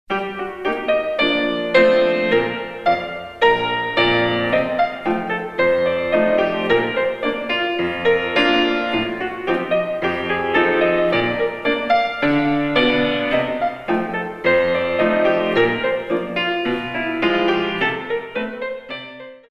In 2
32 Counts